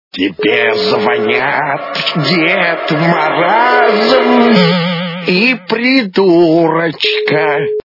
При прослушивании Вам звонит - Дед Мараз и Придурочка! качество понижено и присутствуют гудки.